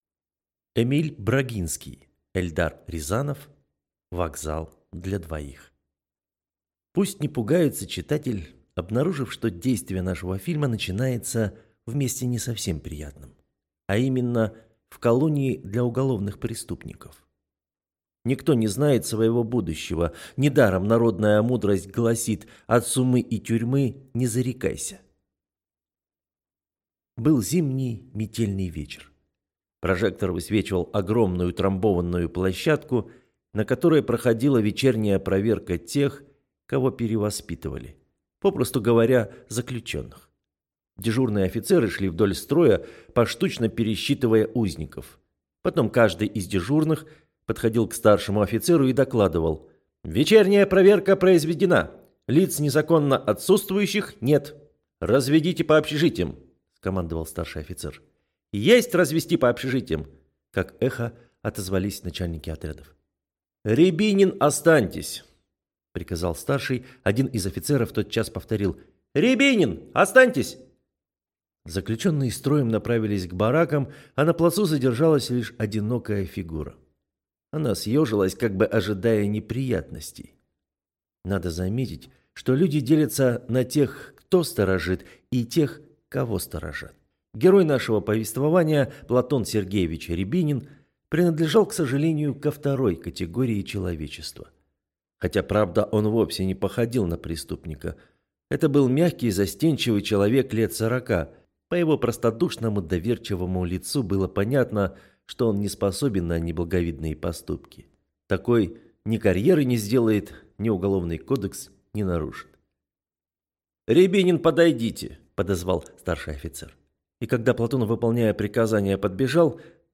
Аудиокнига Вокзал для двоих | Библиотека аудиокниг